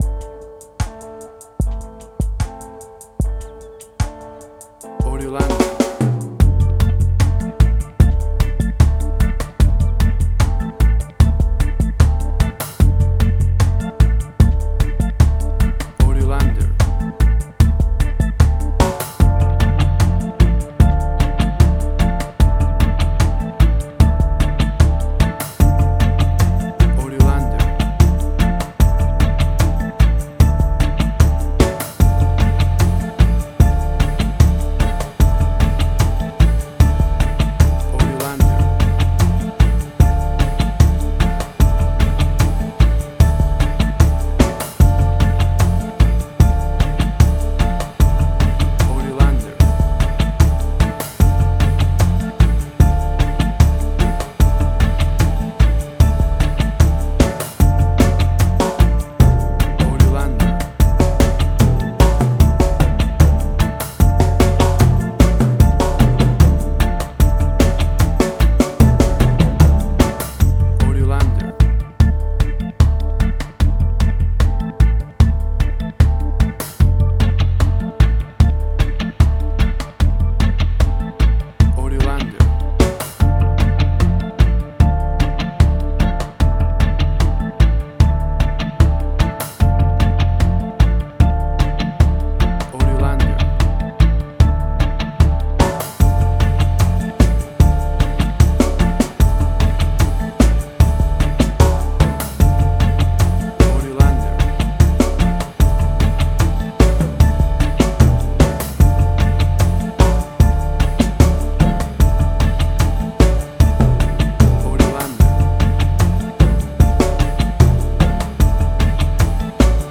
Reggae caribbean Dub Roots
Tempo (BPM): 75